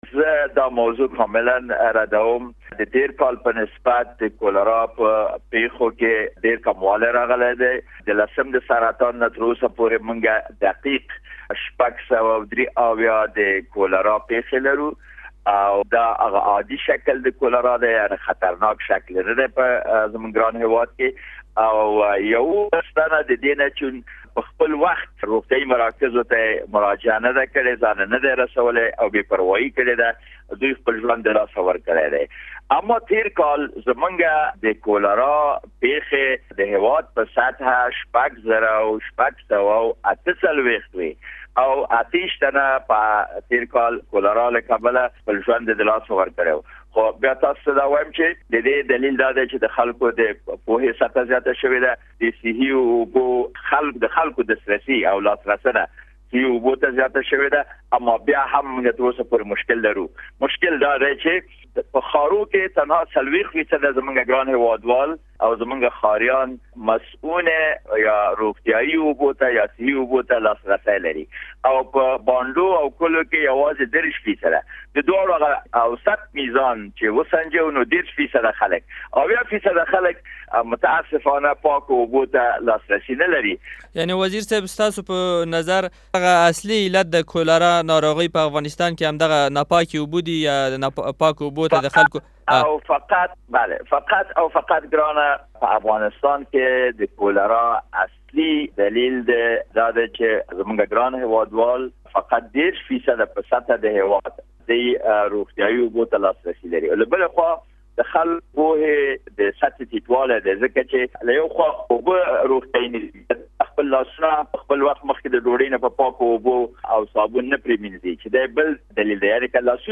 د عامې روغتیا له وزیر سید محمد امین فاطمي سره مرکه واورﺉ